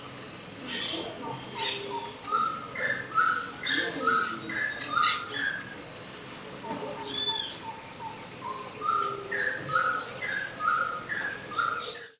Grey-cowled Wood Rail (Aramides cajaneus)
Life Stage: Adult
Detailed location: Merlo
Condition: Wild
Certainty: Recorded vocal